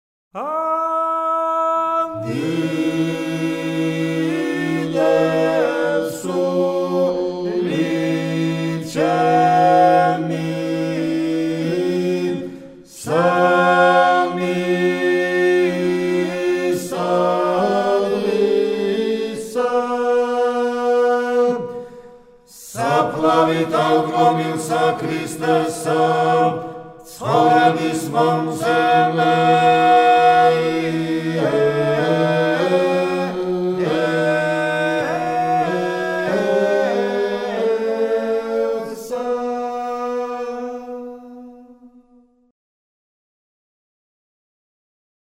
ადიდებს სული ჩემი - საგალობელი
შემოქმედის - გურული